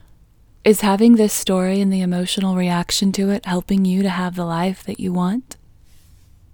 OUT Technique Female English 22